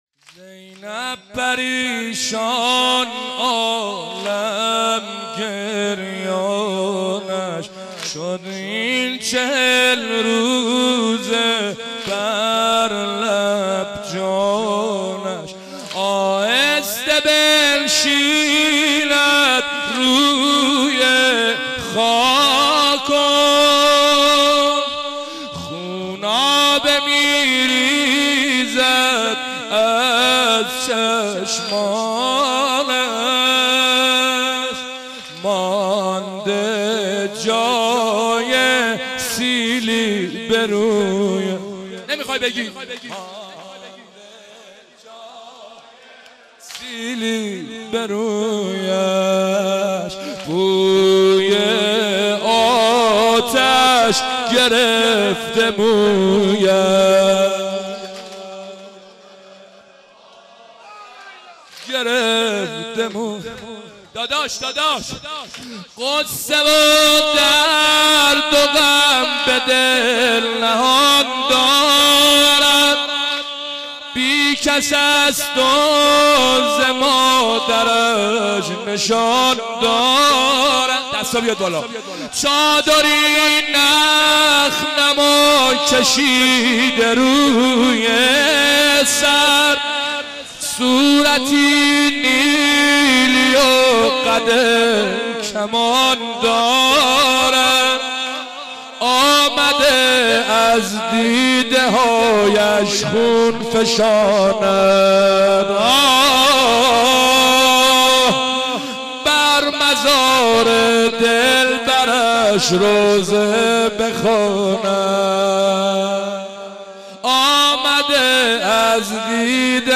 دانلود مداحی اربعین